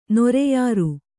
♪ noreyāru